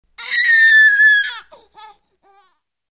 单身婴儿哭泣 | 下载音效.mp3.